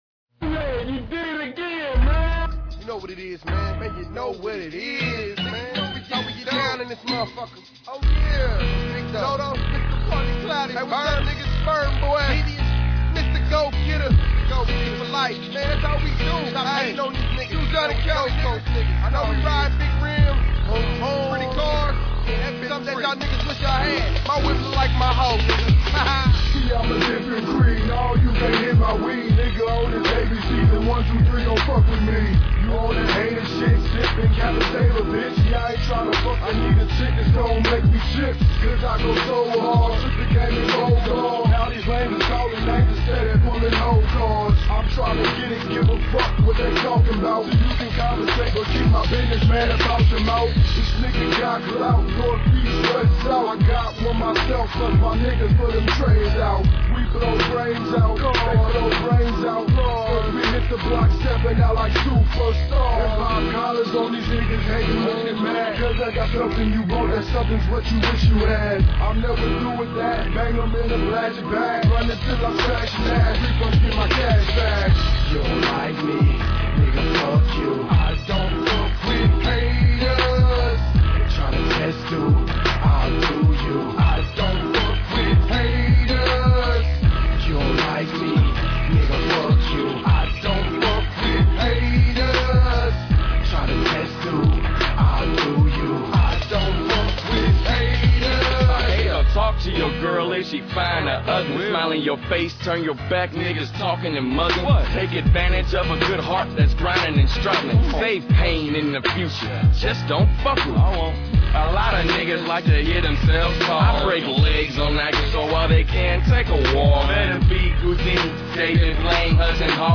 Tags: rap, mp3